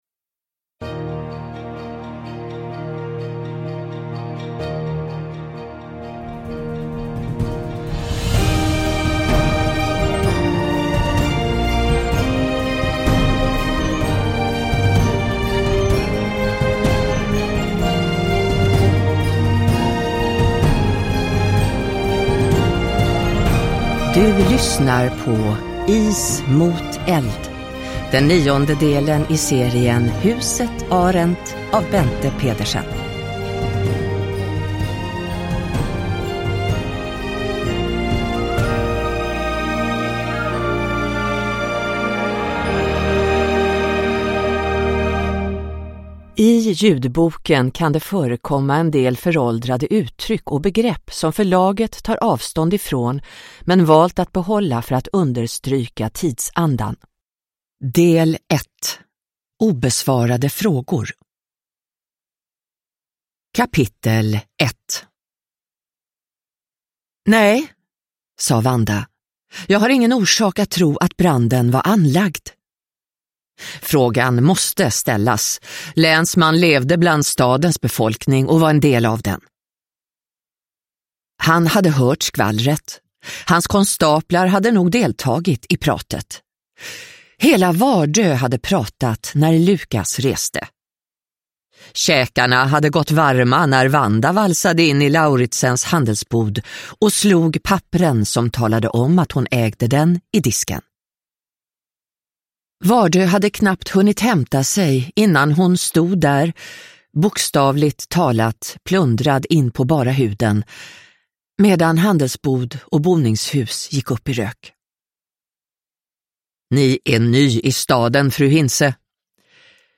Is mot eld – Ljudbok – Laddas ner